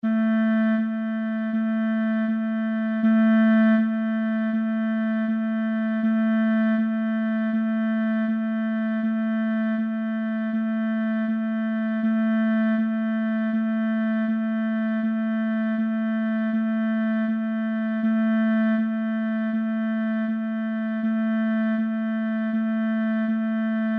NOTAS MUSICAIS
NOTA LA
LA.mp3